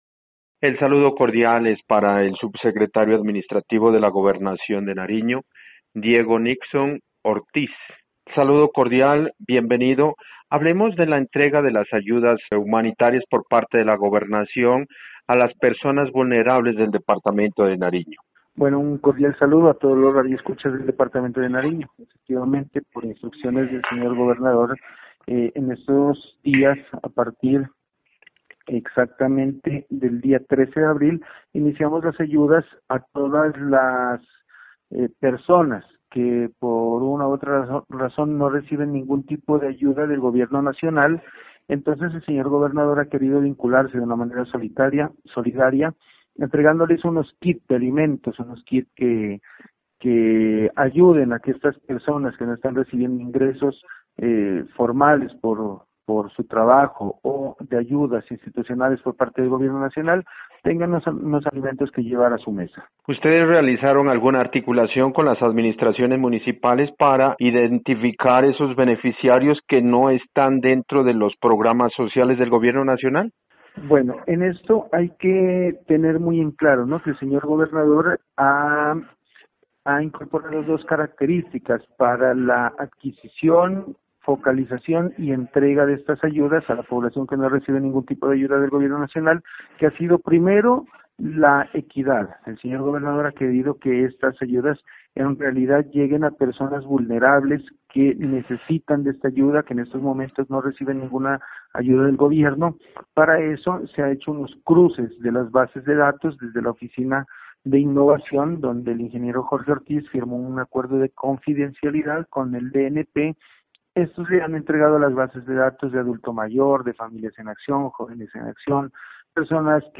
Entrevista con el subsecretario administrativo de la Gobernación de Nariño Diego Nixon Ortiz: